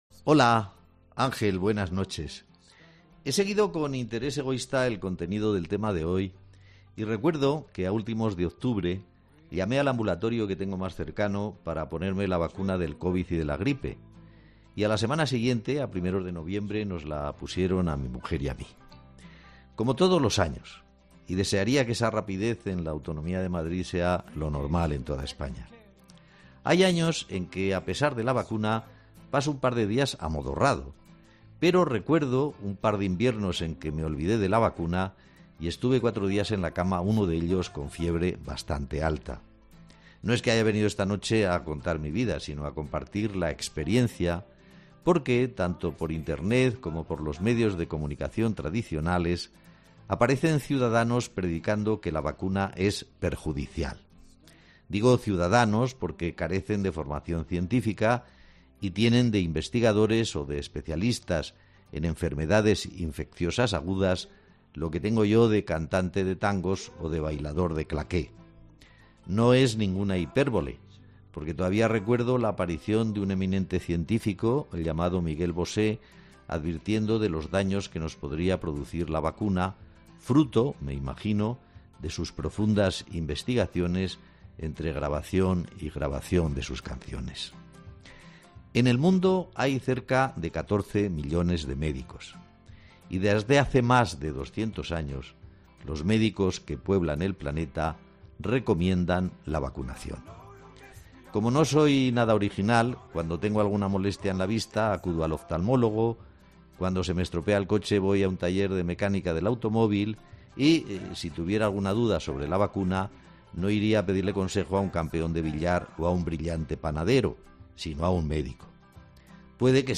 Luis del Val pone el lazo al tema del día de La Linterna
Analista de La Linterna